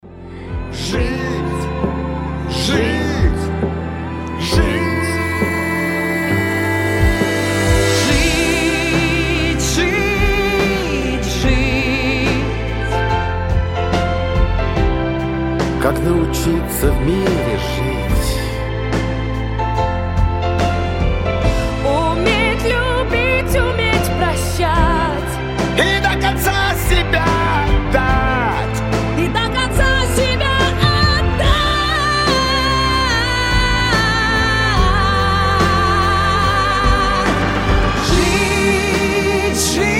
пианино
поп